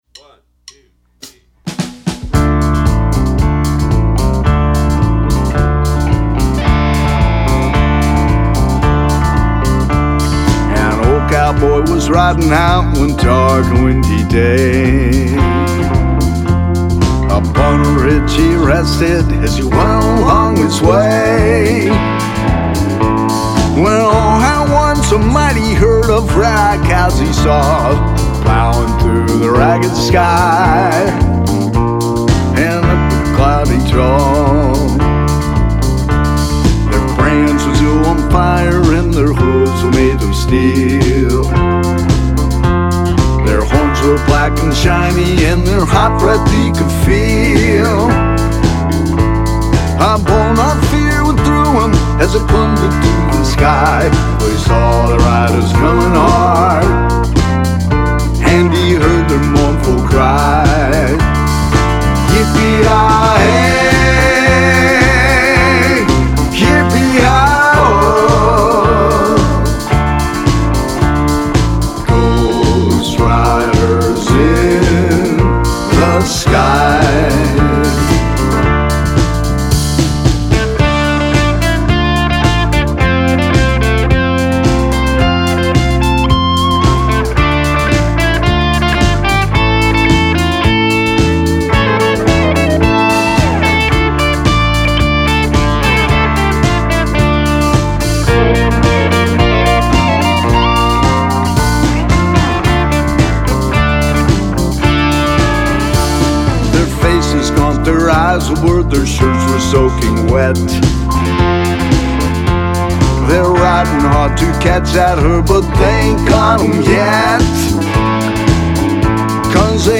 cowboy-styled country/western song